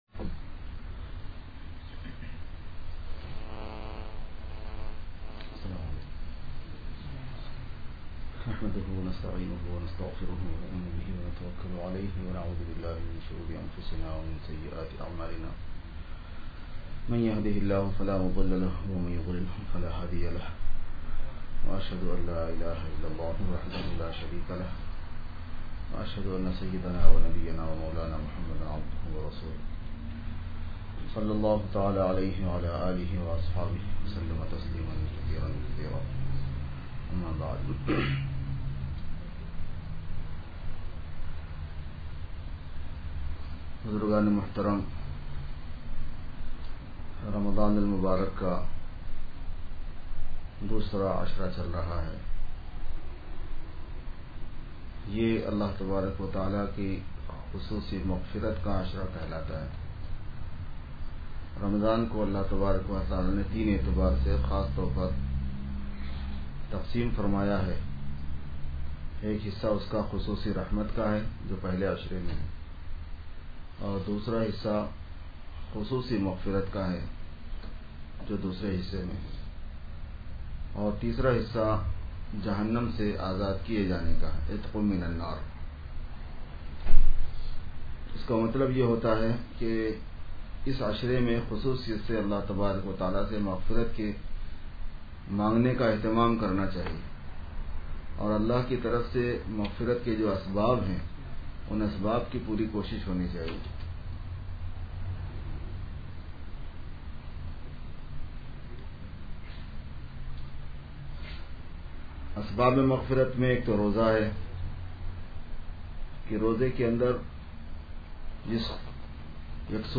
Khutbat e Juma